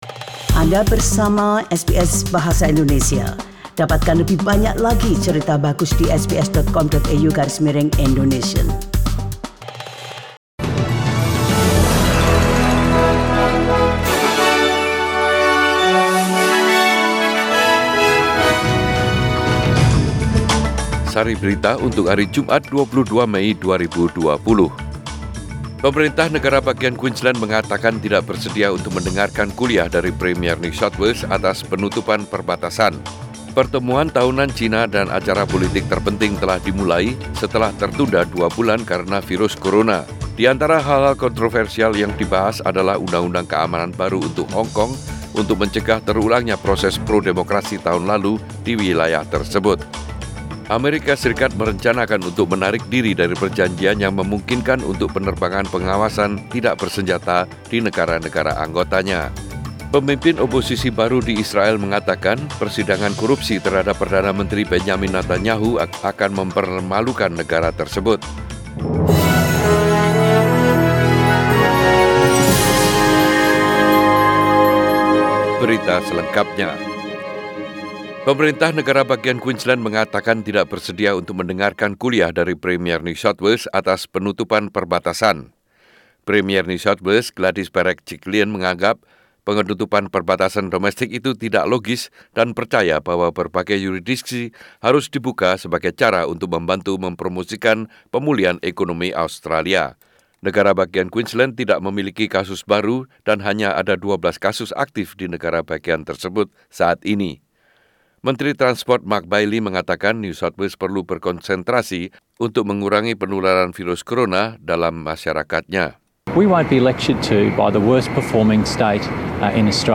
SBS Radio news in Bahasa Indonesia - 22 May 2020